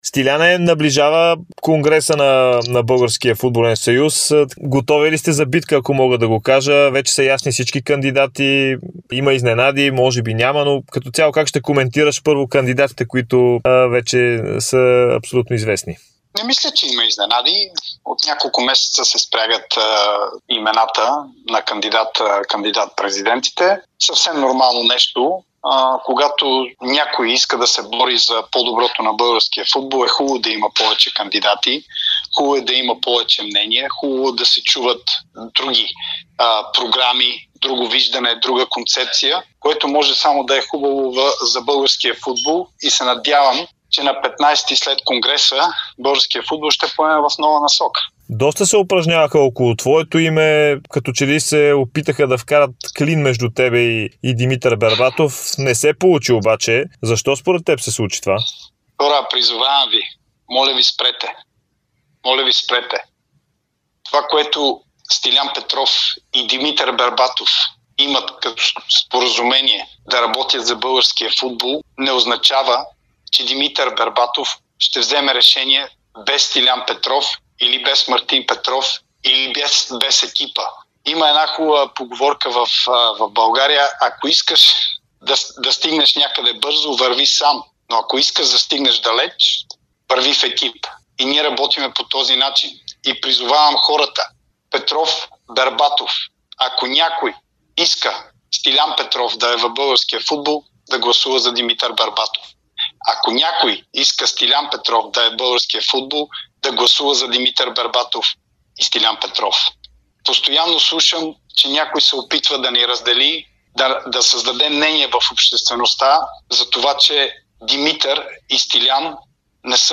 Бившият български национал и част от екипа на Димитър Бербатов в битката за президентския пост в БФС - Стилиян Петров, даде ексклузивно интервю за Дарик радио и dsport, в което говори за наболели теми свързани с Конгреса и българския футбол.